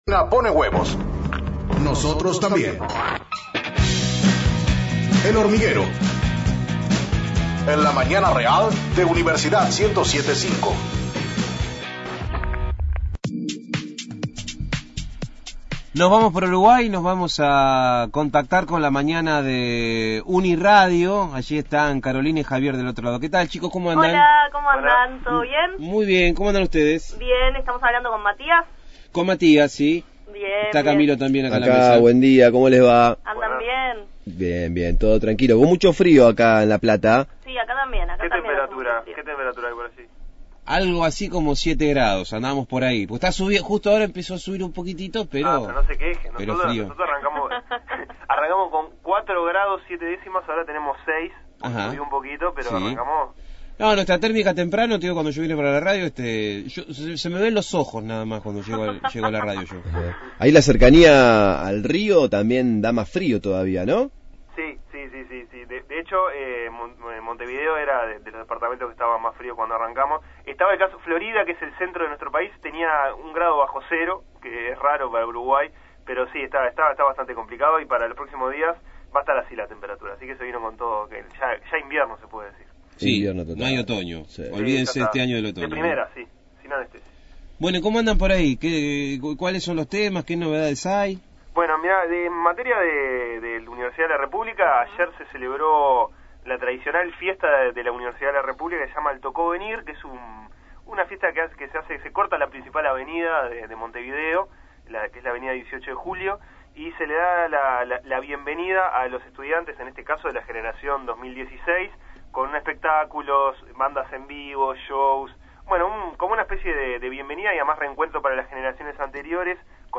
Duplex con UniRadio (Uruguay) sobre temas varios de las dos orillas.